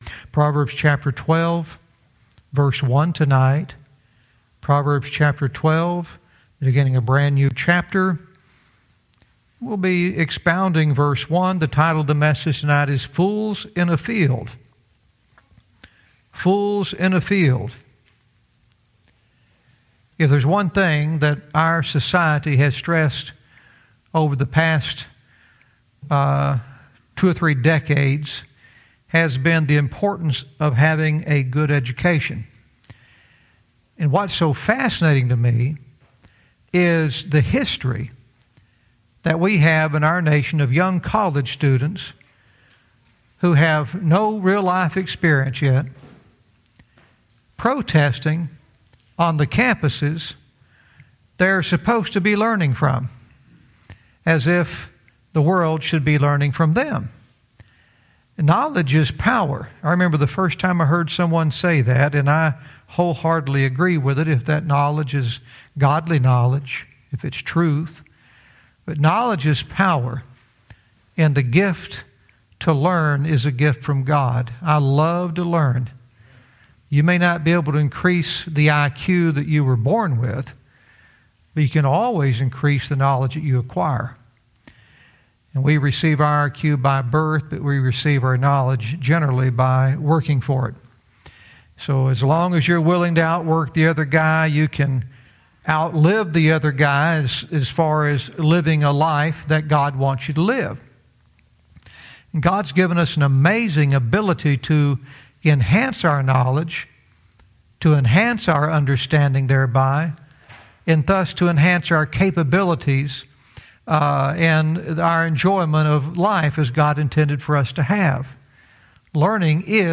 Verse by verse teaching - Proverbs 12:1 "Fools in a Field"